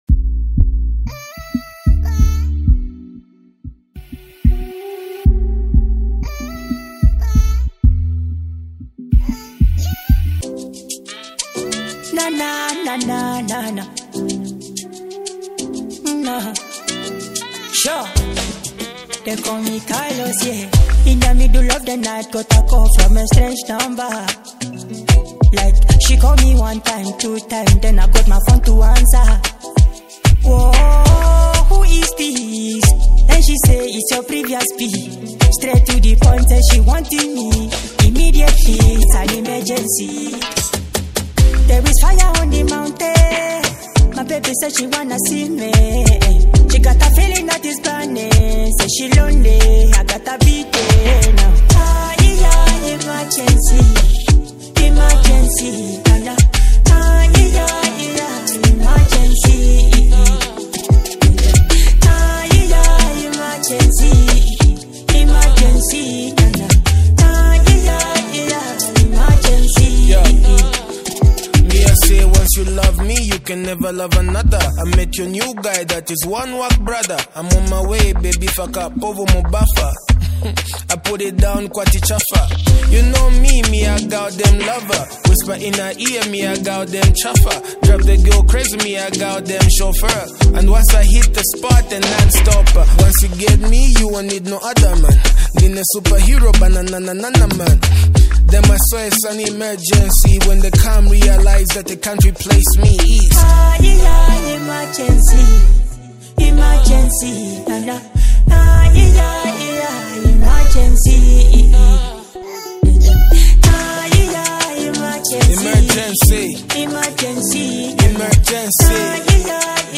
features an intense rhythm and engaging lyrics